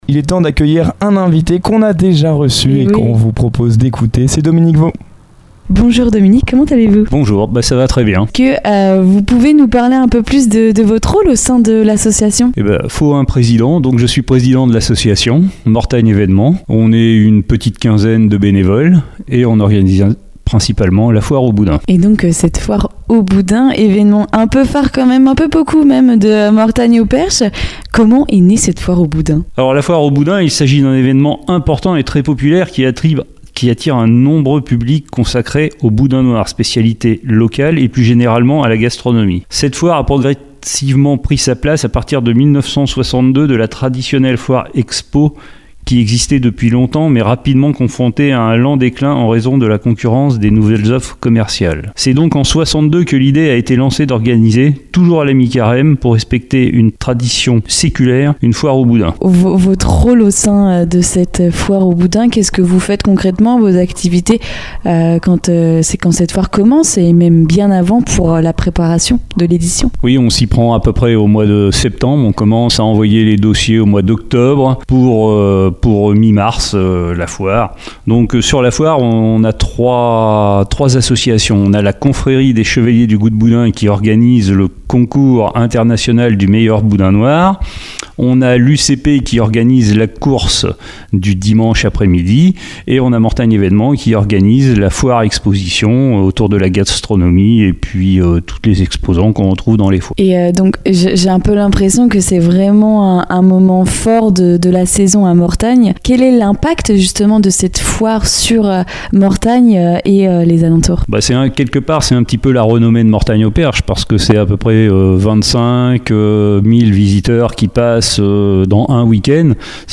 Une interview conviviale et gourmande qui met en lumière un rendez-vous incontournable du territoire, entre tradition, gastronomie et esprit festif.